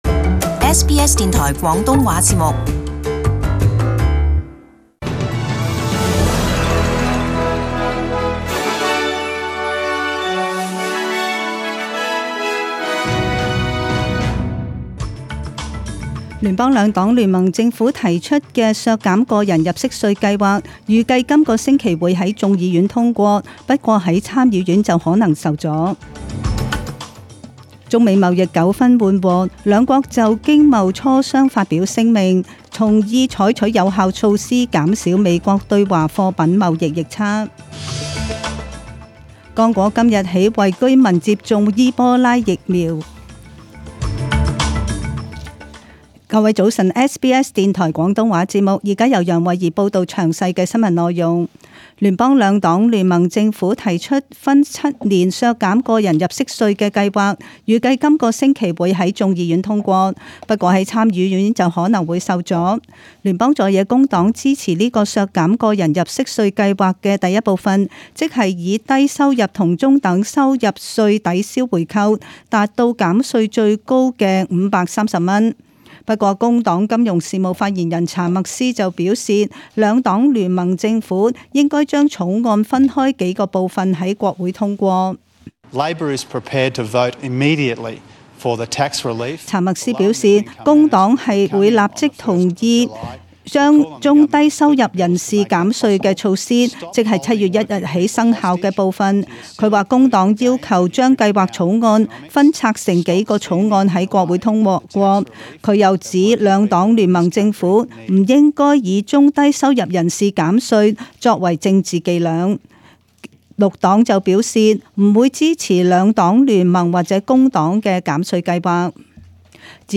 SBS中文新闻 （五月廿一日）